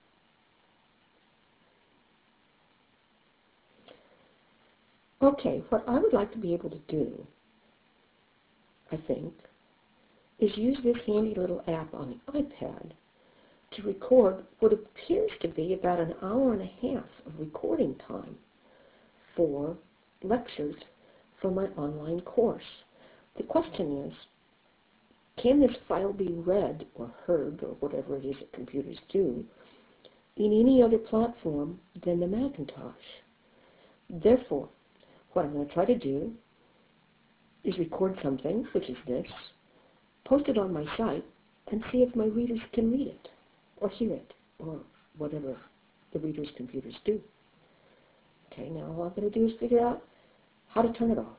There’s a brief silence at the beginning while I tried to tell whether it was running after I turned it on.